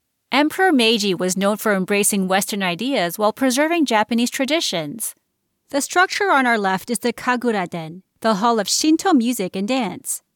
The client says the first 5 seconds sounds louder.